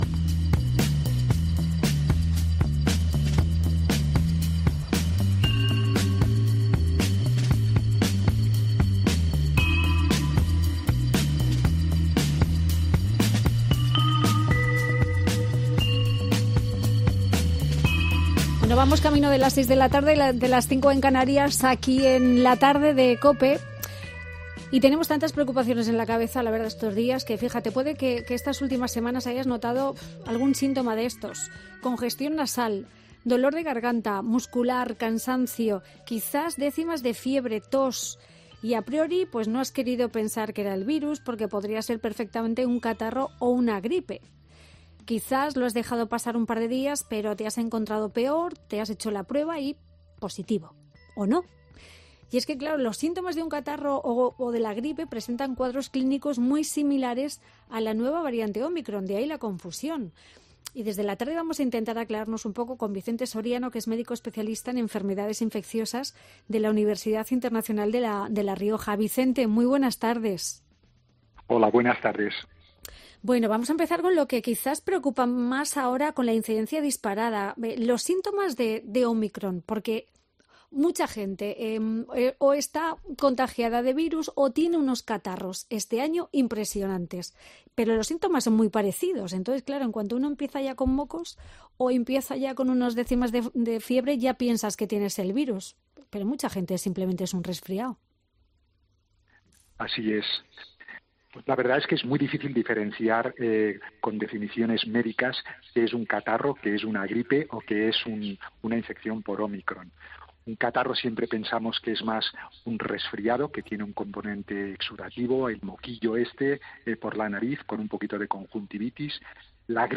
Un doctor nos da en COPE las claves para saber diferenciar entre las tres enfermedades según los síntomas que tengamos